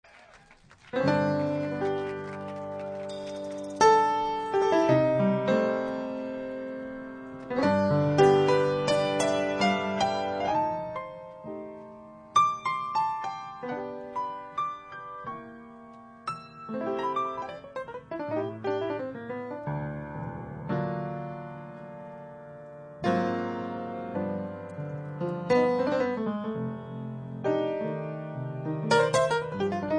Inst